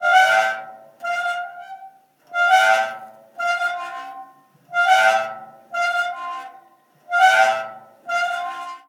Columpio
parque
chirriar
chirrido
metálico
ritmo
Sonidos: Acciones humanas